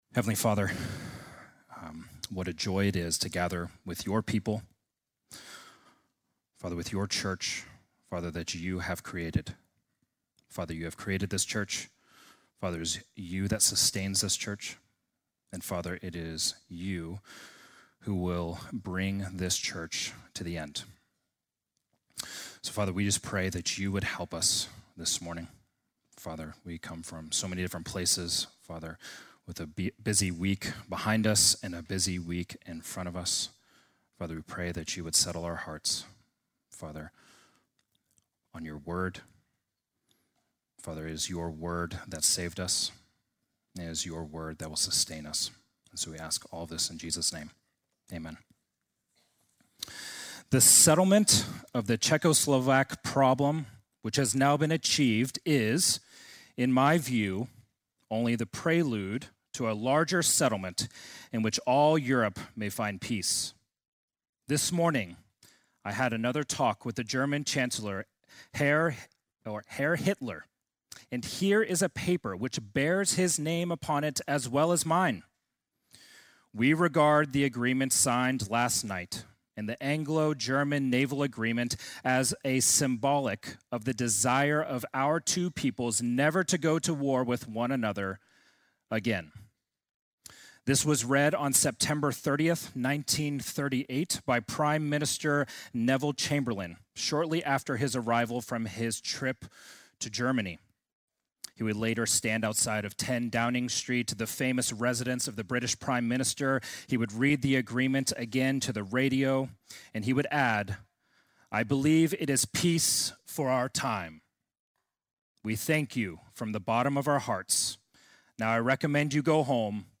Sunday morning message March 8